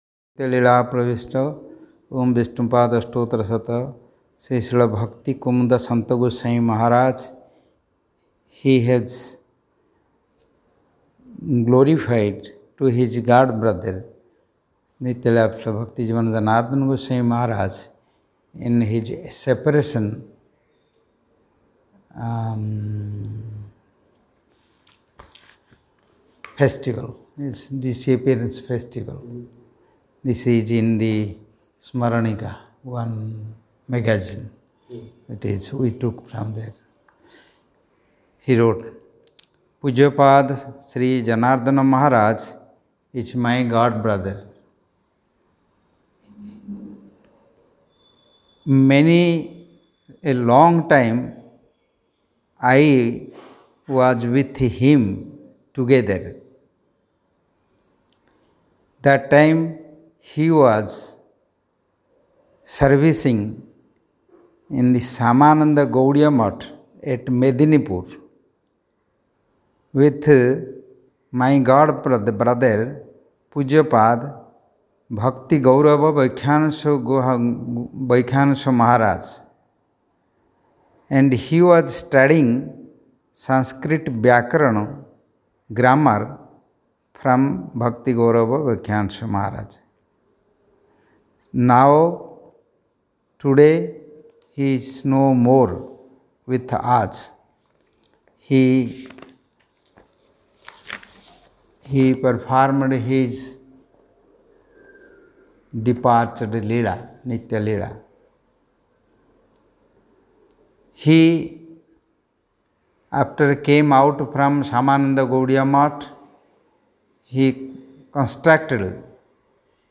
Unedited Audio Recording